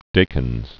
(dākĭnz)